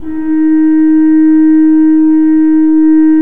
Index of /90_sSampleCDs/Propeller Island - Cathedral Organ/Partition H/KOPPELFLUT M